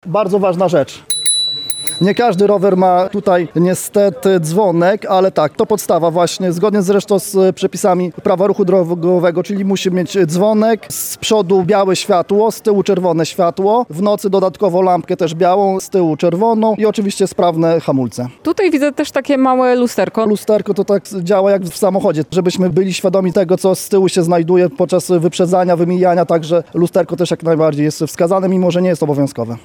Młodzi rowerzyści uczą się zasad ruchu drogowego. Okazją do tego jest piknik „Bezpieczni na drodze”, który trwa na terenie SOS Wiosek Dziecięcych w Lublinie.